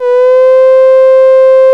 VOICE C4 S.wav